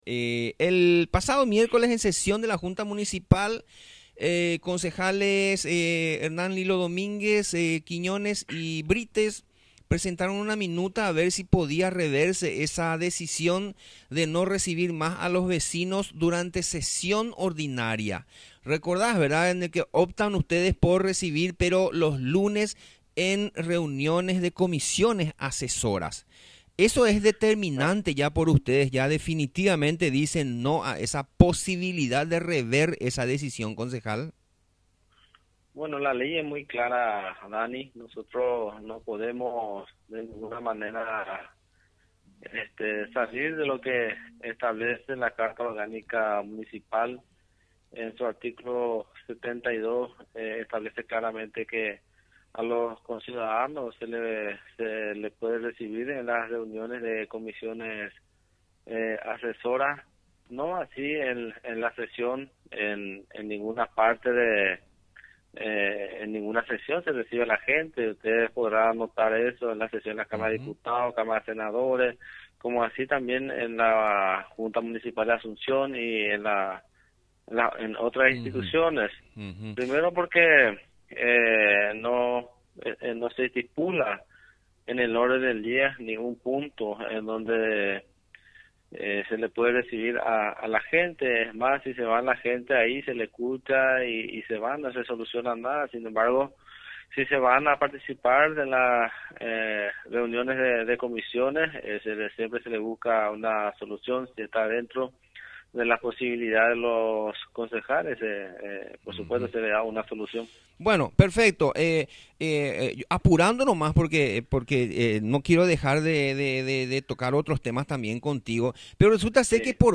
Audio de la entrevista
carlos-ferreira-habla-de-candidatura.mp3